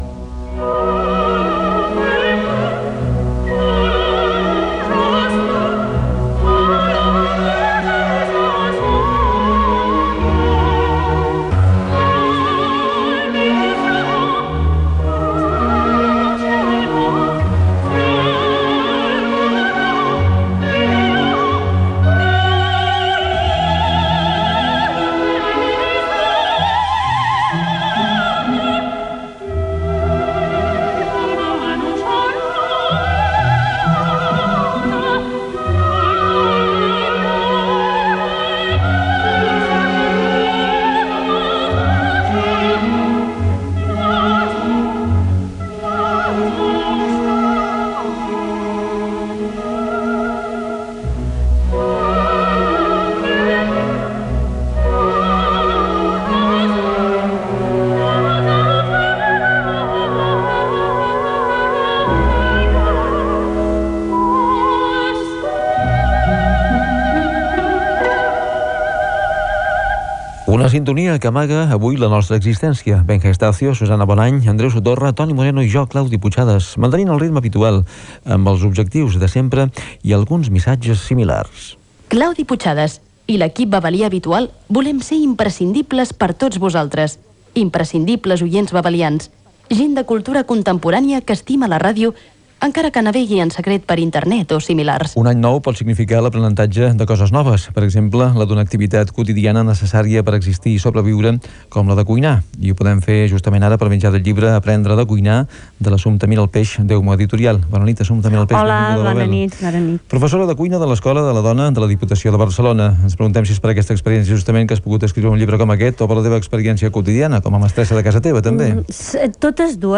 Sintonia, equip, presentació relacionada amb l'any nou, entrevista
FM